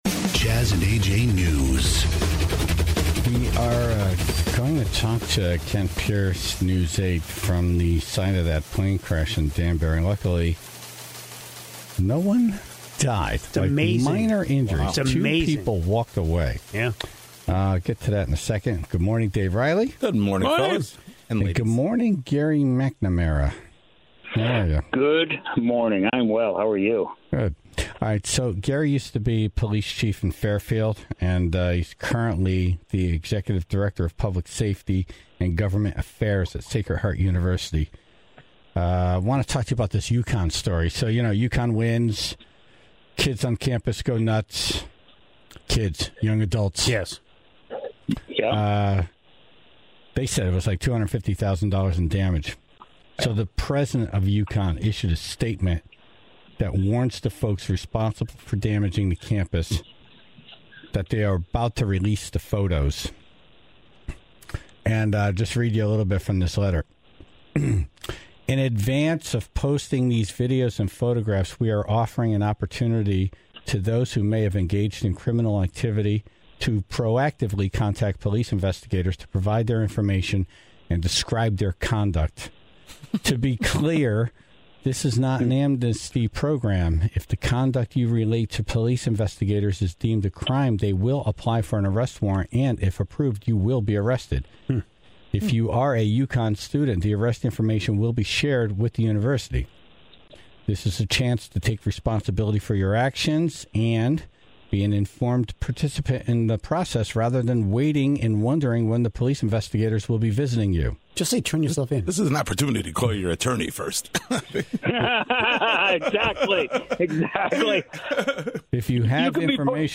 Former Fairfield Police Chief Gary MacNamara was on the phone